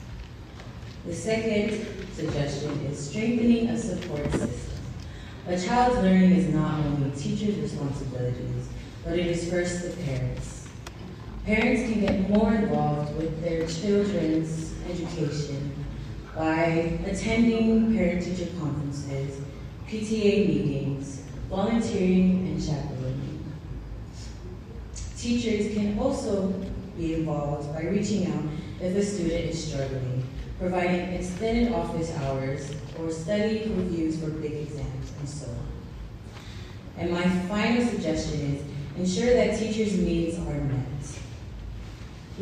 They started with prepared presentations on their selected topics, and then they answered judges questions.
Here are excerpts from the four contestants on the topics of education, drugs, environment and tourism.